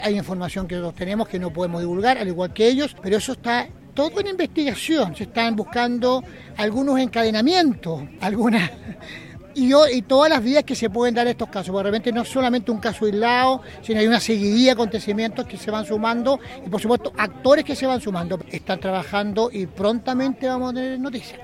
Consultado por Radio Bío Bío, el alcalde de Panguipulli, Rodrigo Valdivia, indicó que está en contacto con los organismos policiales y judiciales, asegurando que pronto habría novedades.
cu-armas-1-alcalde.mp3